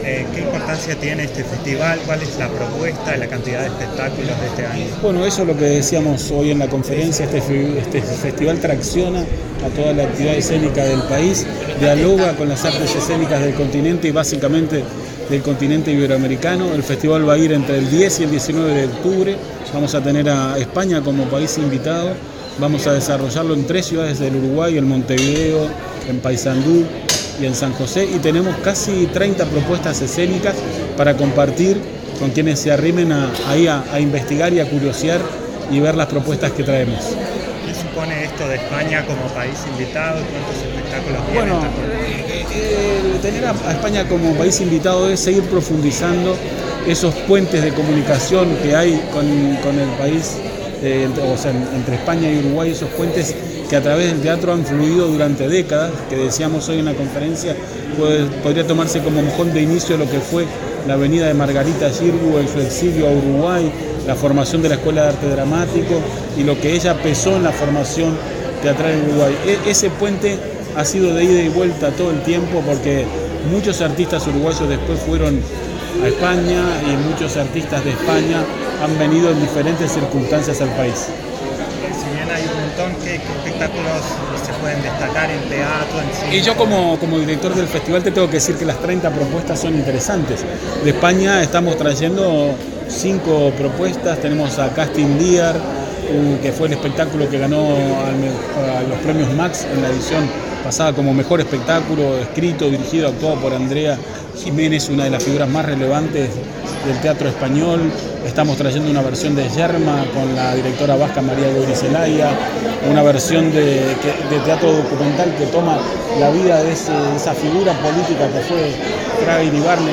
Declaraciones del coordinador del Instituto Nacional de Artes Escénicas (INAE), Gustavo Zidan 12/09/2025 Compartir Facebook Twitter Copiar enlace WhatsApp LinkedIn En el marco del lanzamiento del noveno Festival de Artes Escénicas del Uruguay, el coordinador del Instituto Nacional de Artes Escénicas (INAE), Gustavo Zidan, realizó declaraciones a los medios de prensa.